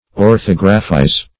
Search Result for " orthographize" : The Collaborative International Dictionary of English v.0.48: Orthographize \Or*thog"ra*phize\, v. t. To spell correctly or according to usage; to correct in regard to spelling.